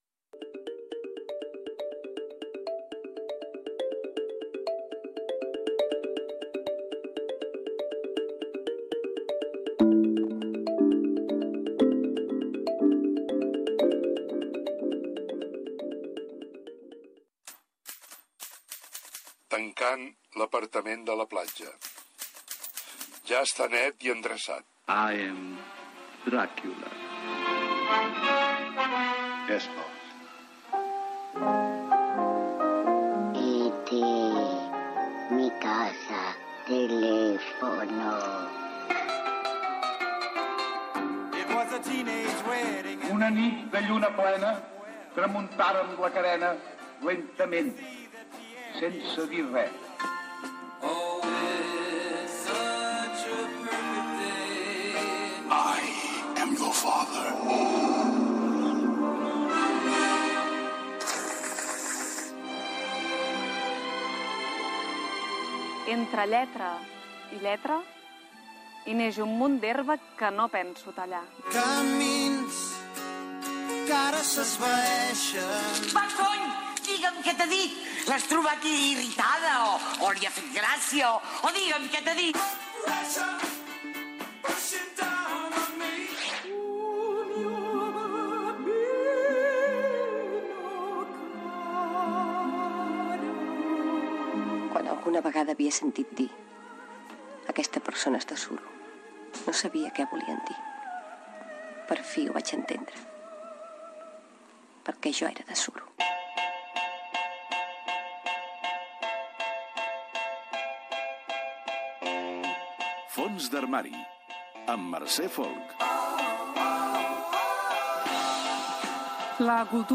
Careta del programa, presentació, recull de declaracions del cantant i pintor Manolo García i entrevista Gènere radiofònic Entreteniment